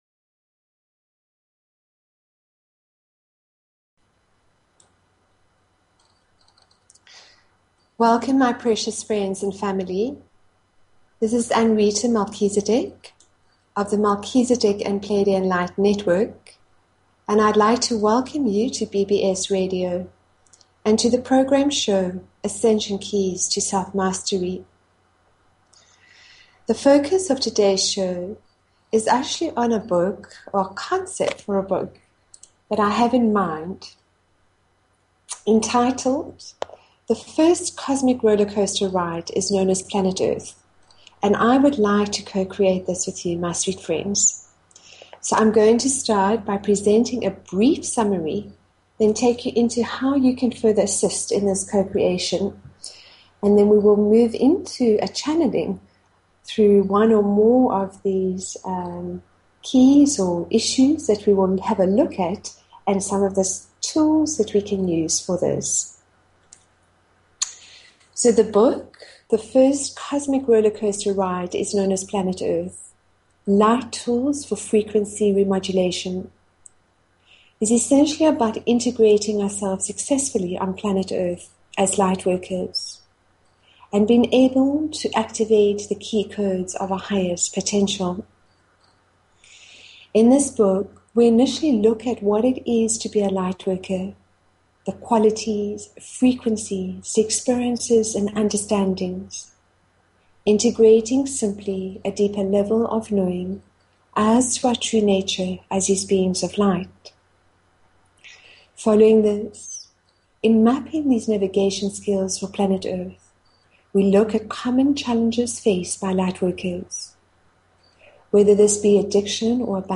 Talk Show Episode, Audio Podcast, Ascension_Keys_to_Self_Mastery and Courtesy of BBS Radio on , show guests , about , categorized as